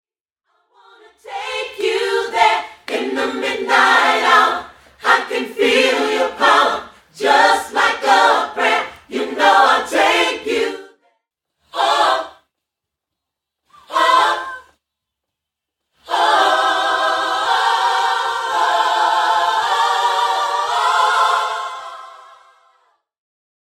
Studio Choirs Stem